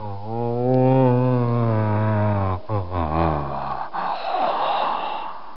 Horror_3.mp3